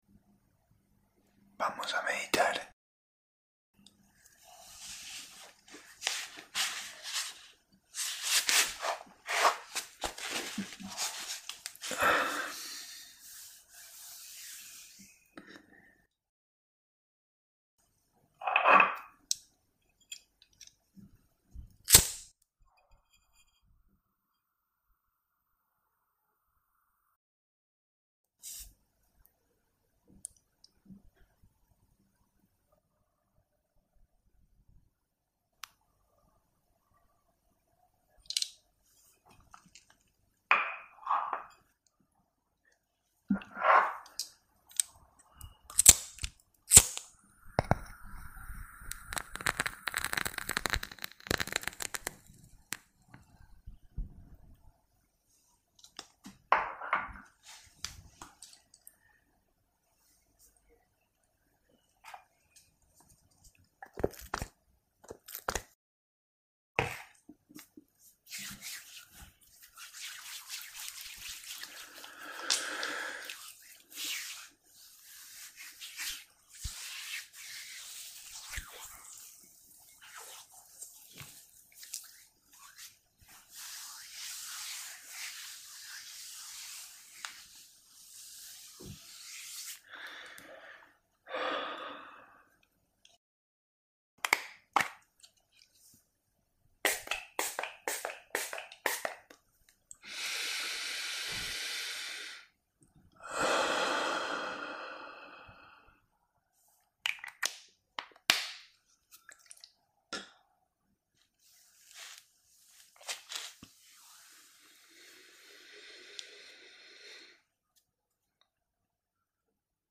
Meditación en tiempo real para conectar en la misma frecuencia Hosted on Acast.